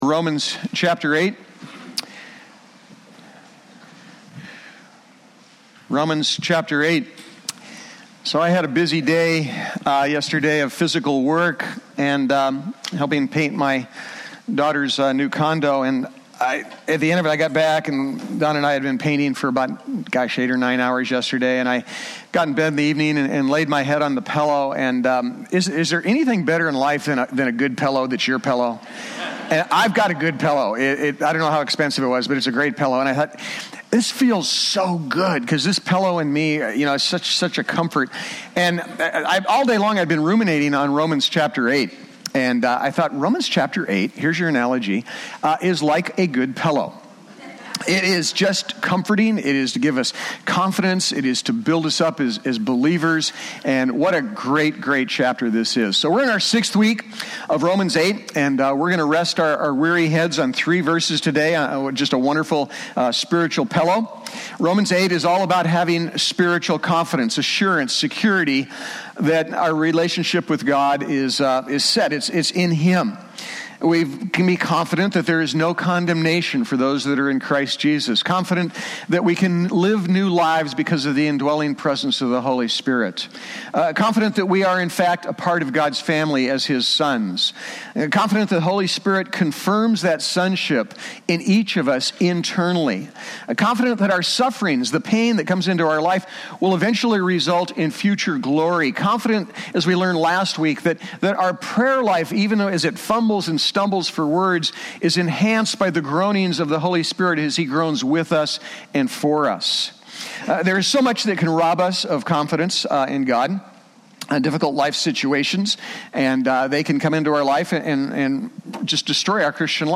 Romans 8:28-30 Service Type: Sunday Topics